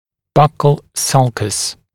[ˈbʌkl ˈsʌlkəs][ˈбакл ˈсалкэс]переходная складка, щёчная борозда